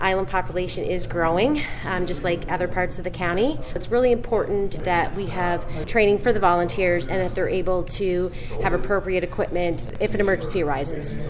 Councillor Darla MacKeil says council recognizes that the Fire Department has unique needs, as timely mutual aid isn’t available for the isolated island.